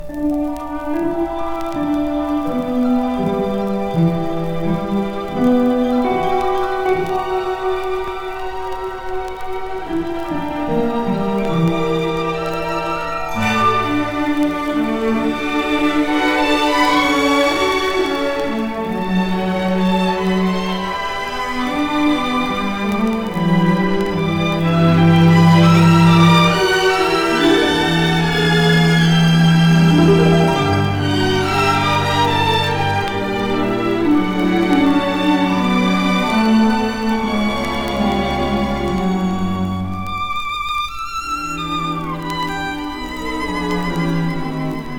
美しい旋律と優しいムードに包まれる爽やかな良盤です。